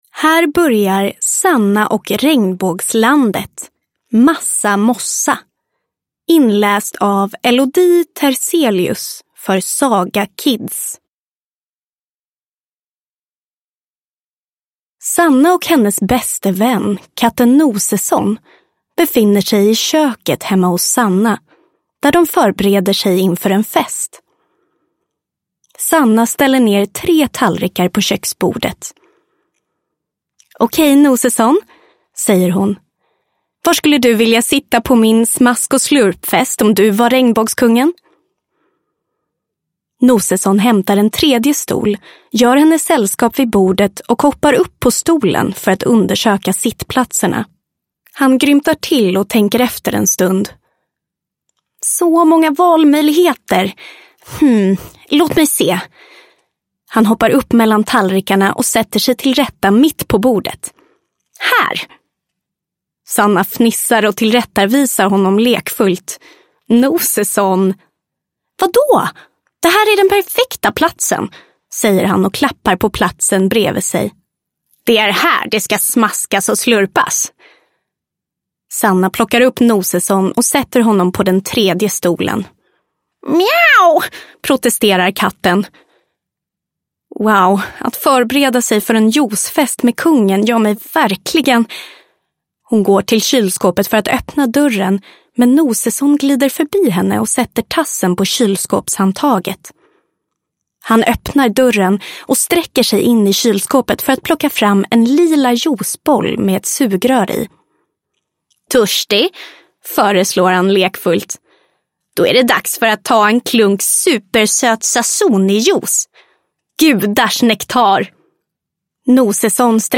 Sanna och Regnbågslandet – Massa mossa – Ljudbok